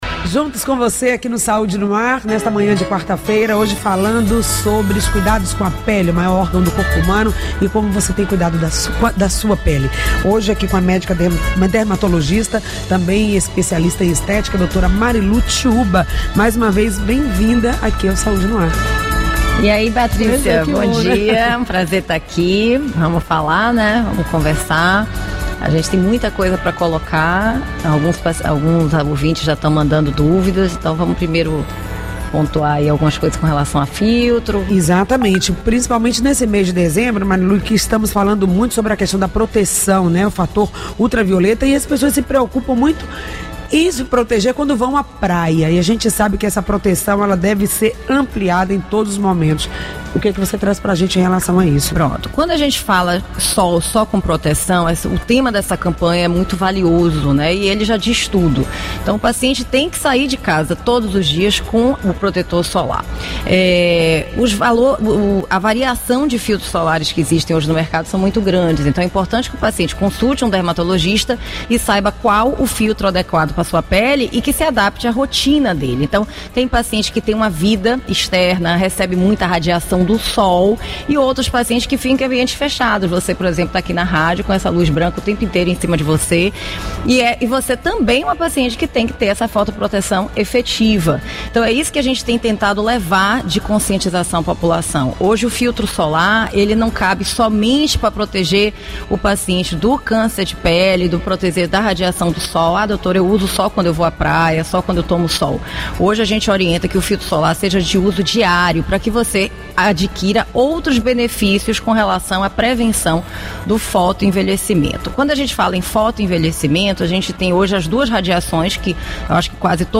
O programa foi transmitido pela Rádio Excelsior da Bahia AM 840 e por nossa Rádio Web , no horário das 08h às 09H.